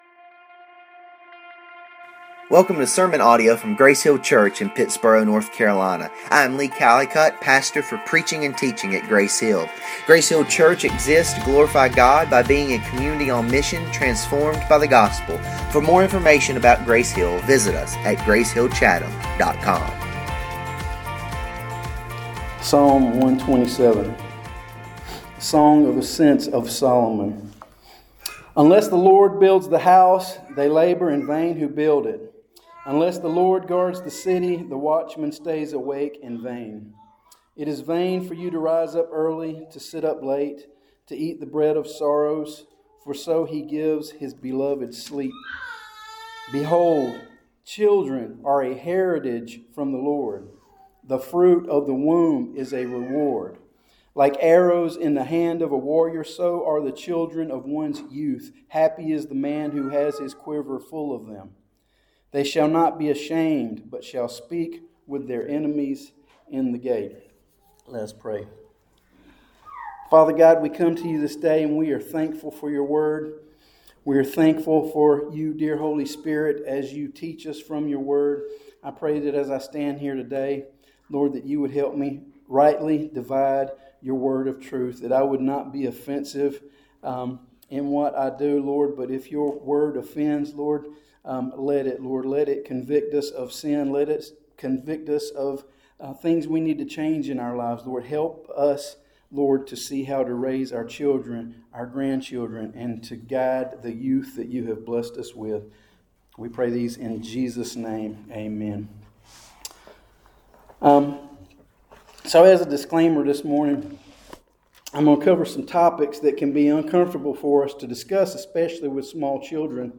Listen to this sermon how raising and disciplining our children.